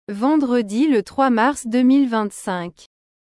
vendredi le 3 mars 2025ヴァォンドレディ ル トロワ マァルス ドゥミルヴァンサンク